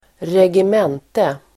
Uttal: [regem'en:te]